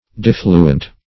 Search Result for " diffluent" : The Collaborative International Dictionary of English v.0.48: Diffluent \Dif"flu*ent\, a. [L. diffluens, p. pr. of diffluere to flow off; dif- = dis- + fluere to flow.]